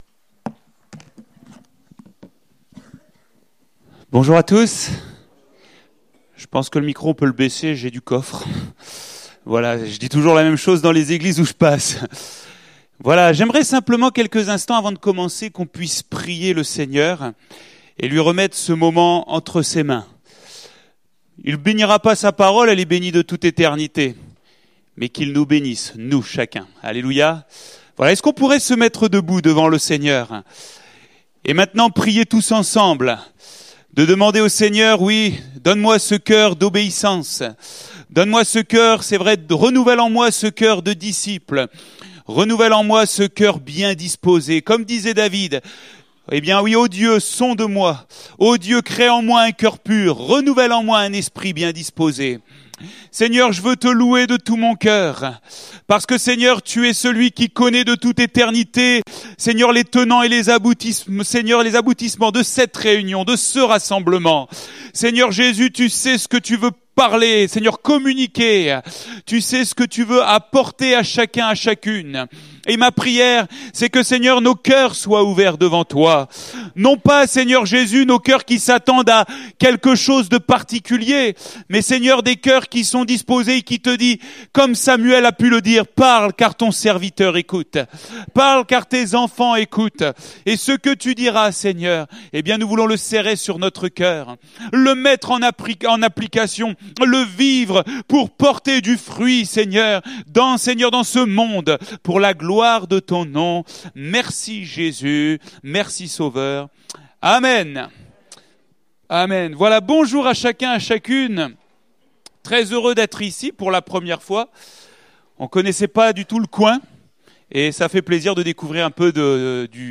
Date : 27 janvier 2019 (Culte Dominical)